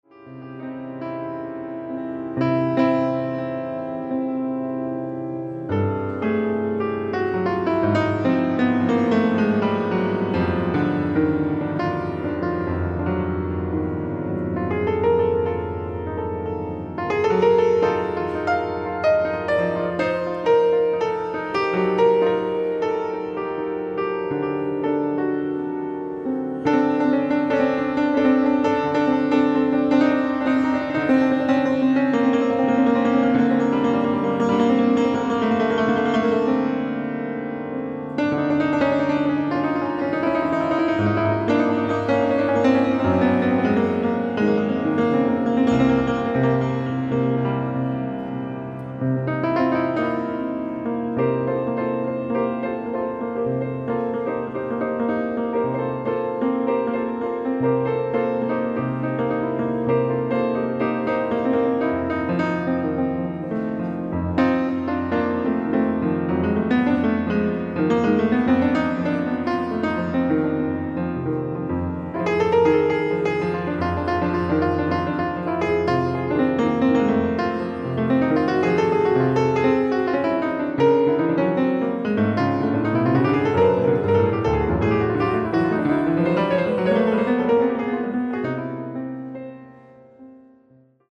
ライブ・アット・ザールフェルデン、オーストリア 08/25/2001
オフィシャル級のデジタル放送音源！！
※試聴用に実際より音質を落としています。